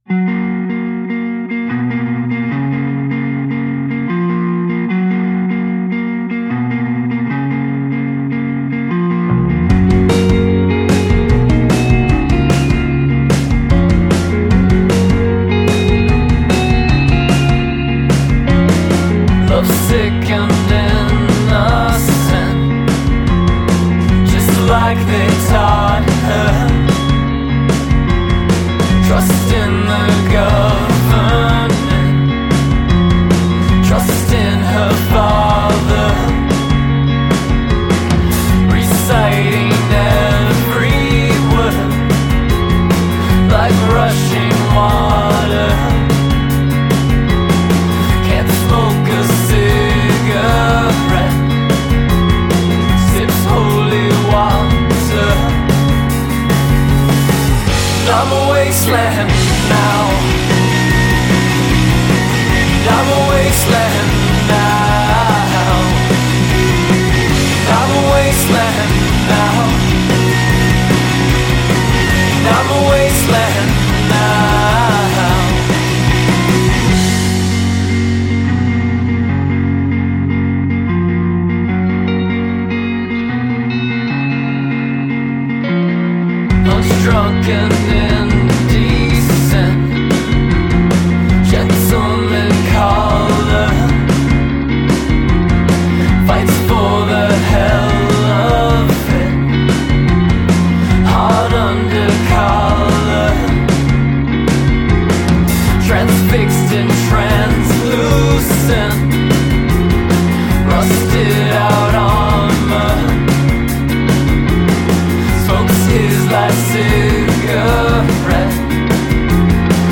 indie rock band